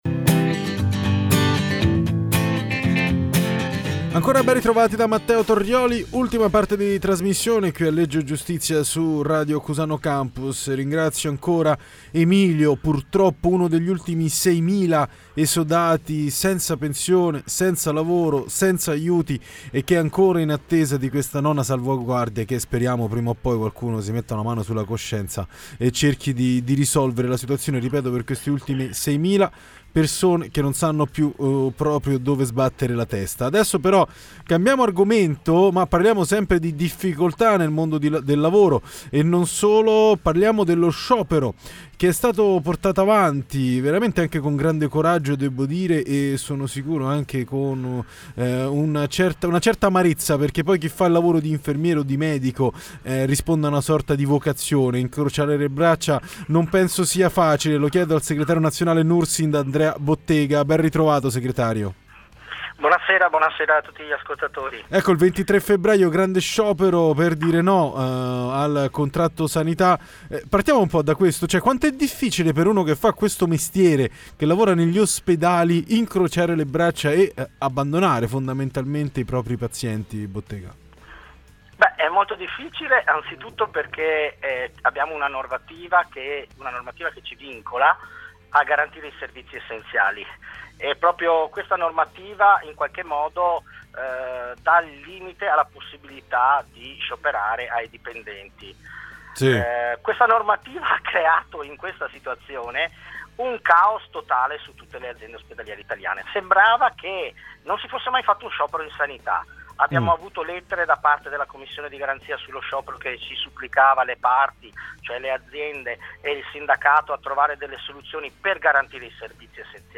DA RADIO CUSANO CAMPUS intervista